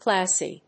音節class・y 発音記号・読み方
/klˈæsi(米国英語), klάːsi(英国英語)/